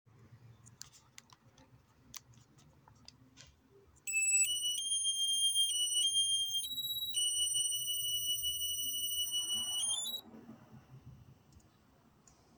DREAM'S POWER UP SOUND